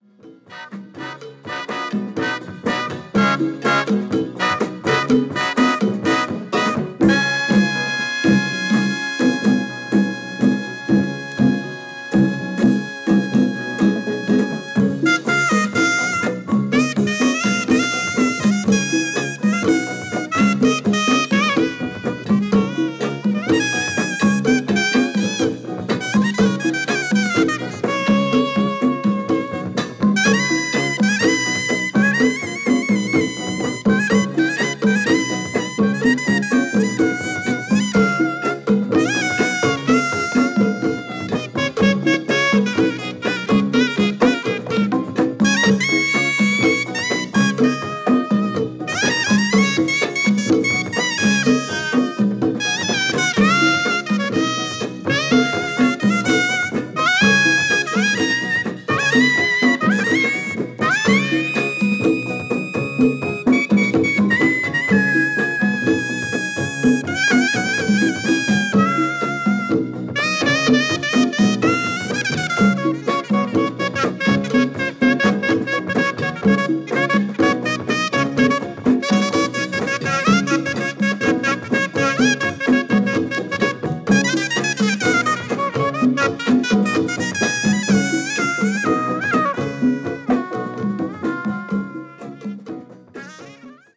７１年リリース・ラテンジャズ！！！
Electric Bass
Percussion
Trombone